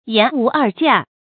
言無二價 注音： ㄧㄢˊ ㄨˊ ㄦˋ ㄐㄧㄚˋ 讀音讀法： 意思解釋： 原指賣商品時價格沒有虛頭；不討價還價。